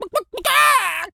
chicken_cluck_to_scream_02.wav